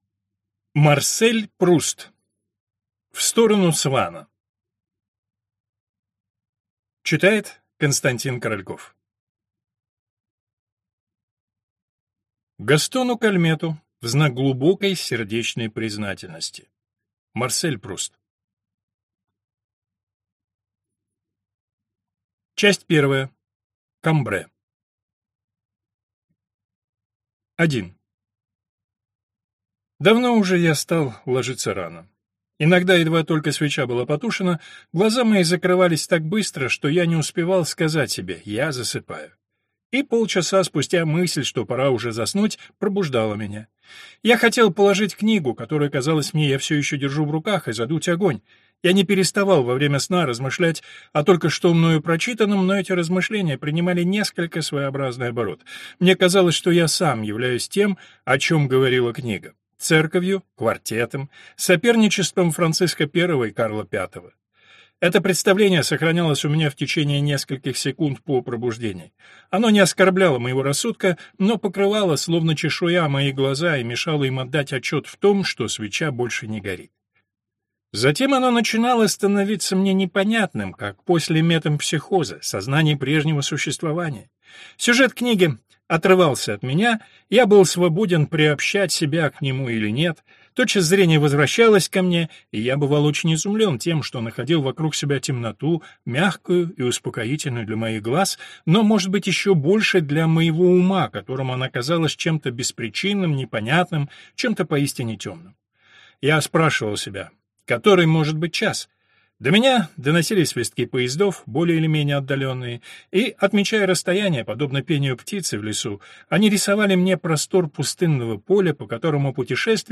Аудиокнига В сторону Свана | Библиотека аудиокниг